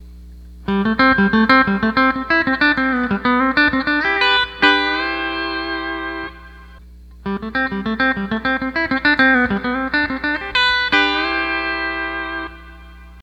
Steel Guitar Tab Page 18 / Lessons
Tab 630 - E9 - Palm Blocking Warm Up Riff